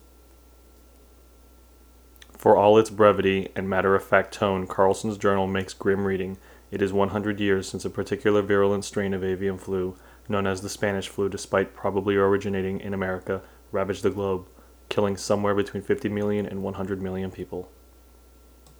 Here are two readings.
Gain is at 3 o’clock.